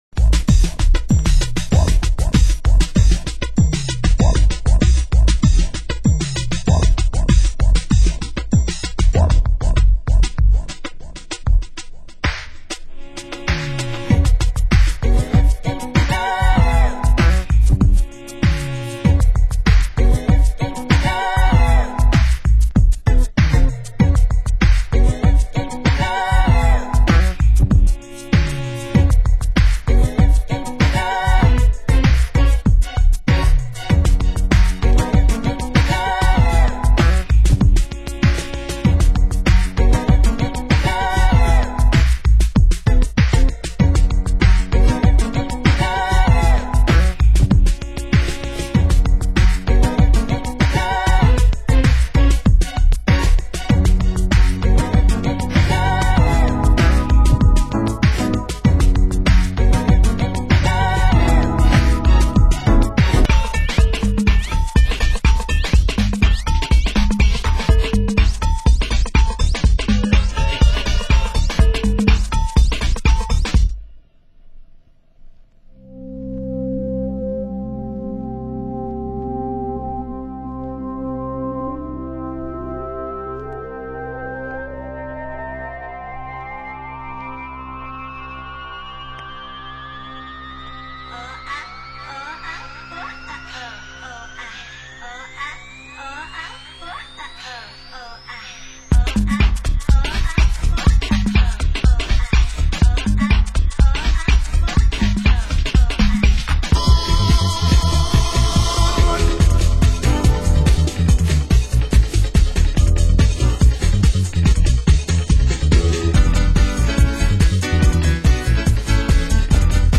Genre: Experimental